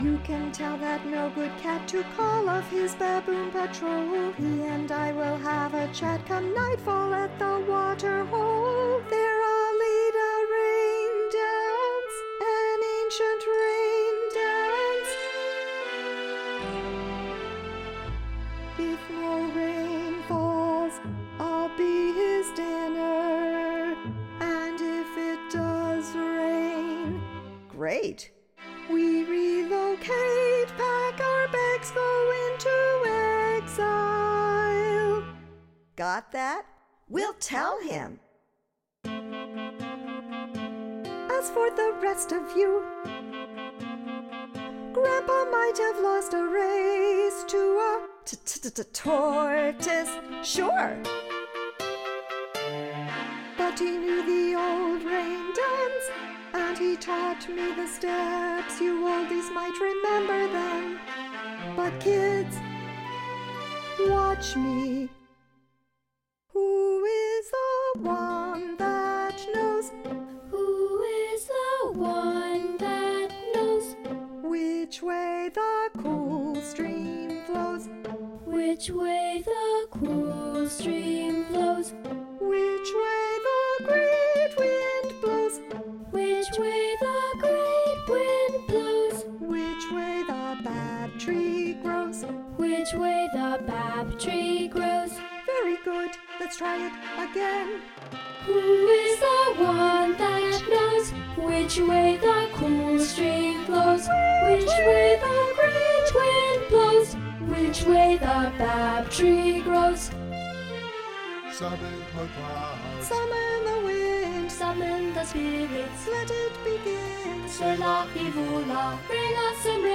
Inspired by the music of South Africa, Rain Dance is a 75-minute, humorous, entirely-sung political drama inspired by Tish Farrell's story, "The Hare Who Would Not Be King".